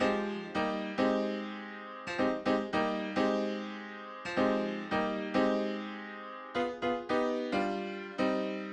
13 Piano PT 1-4.wav